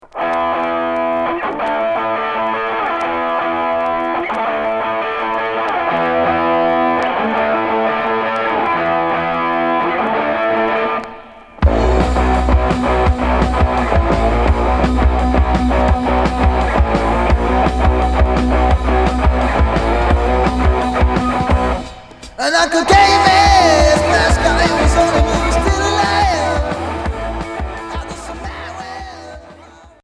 Hard heavy Unique 45t retour à l'accueil